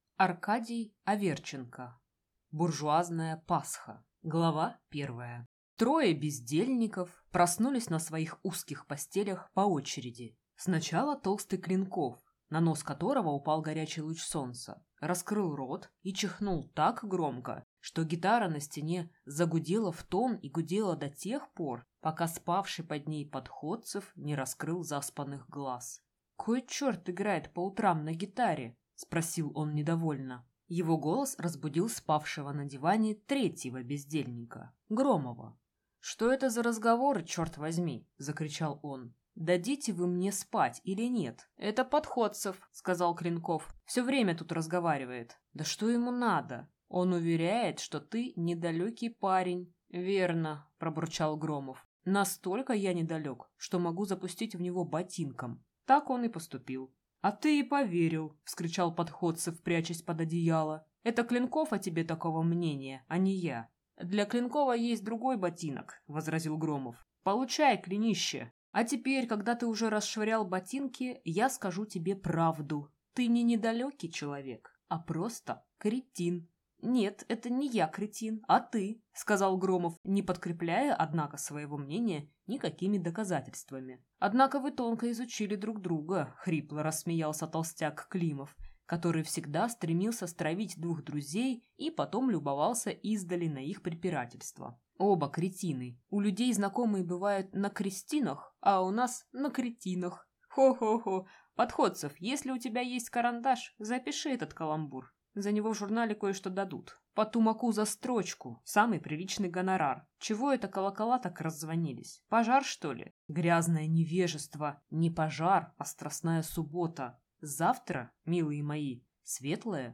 Аудиокнига Буржуазная Пасха | Библиотека аудиокниг
Прослушать и бесплатно скачать фрагмент аудиокниги